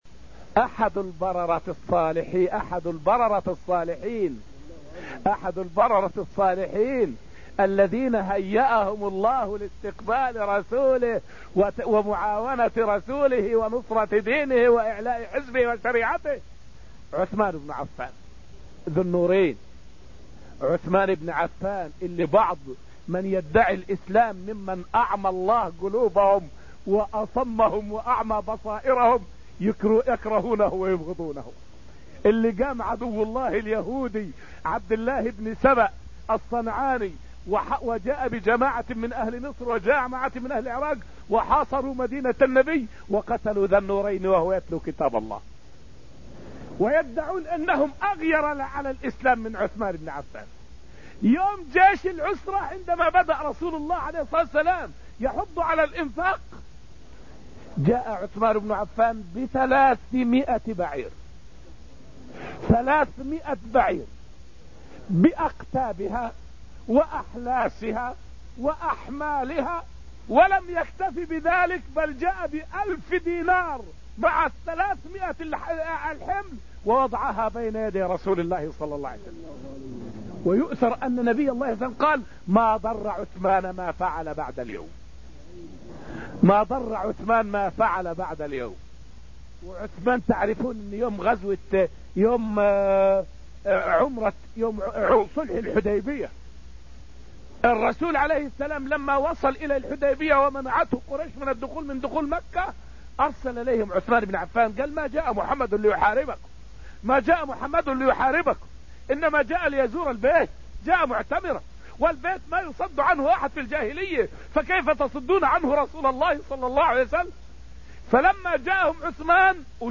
فائدة من الدرس الثالث والعشرون من دروس تفسير سورة البقرة والتي ألقيت في المسجد النبوي الشريف حول فضل النبي عليه السلام والصحابة في تبليغ الرسالة.